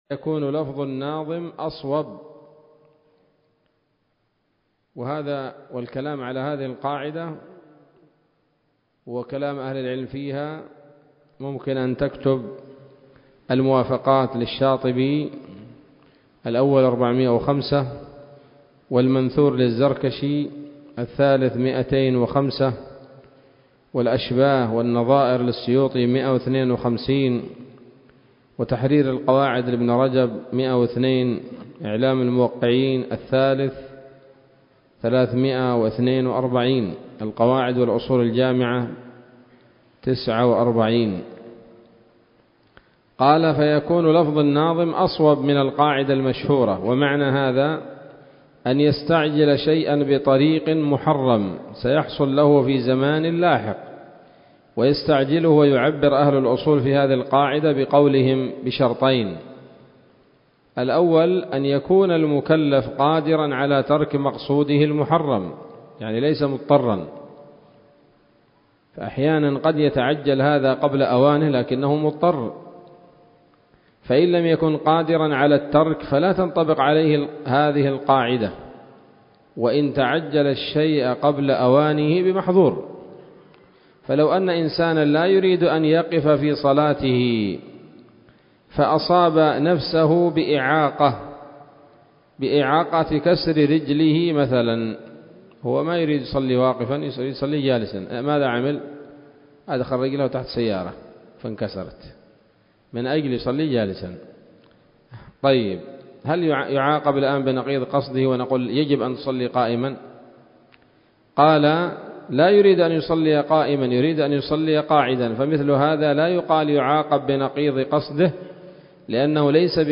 تنبيه: في انقطاع يسير من أول الشرح